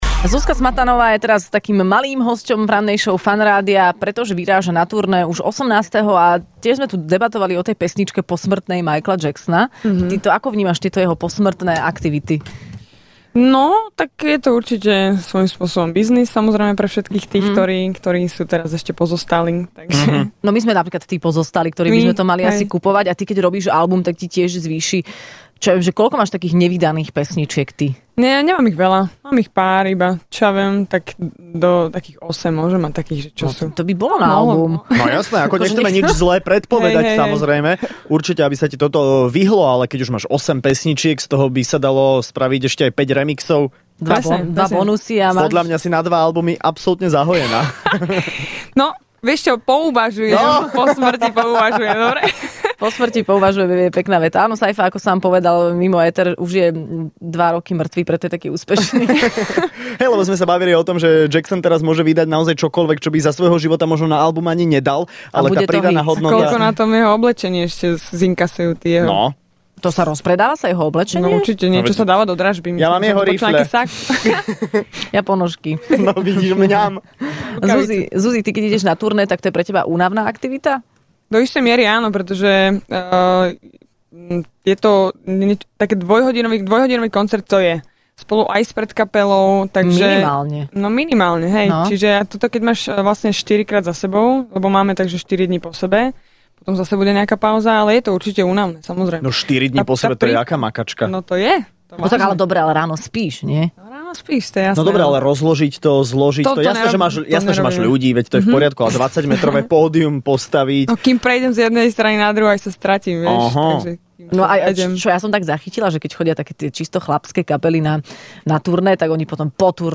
V Rannej šou boli hosťami finalisti šou Česko - Slovensko má talent ale okrem nich prišla aj Zuzka Smatanová...